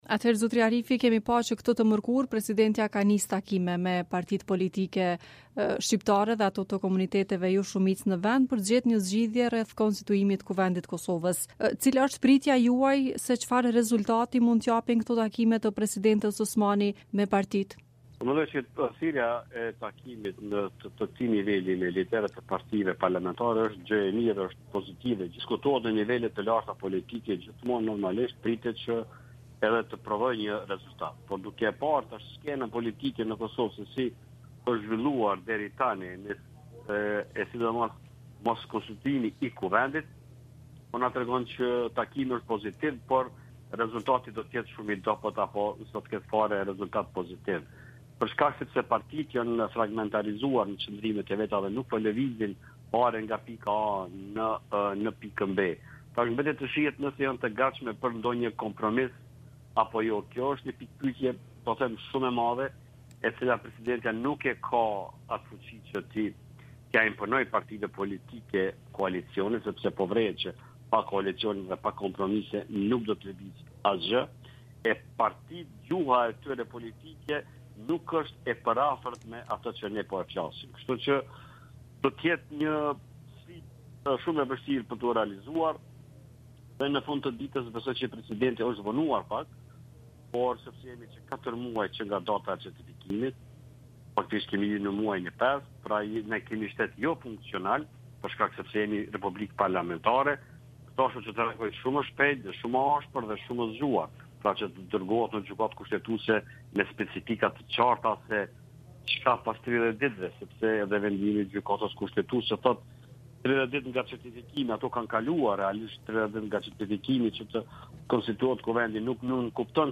Intervista e plotë: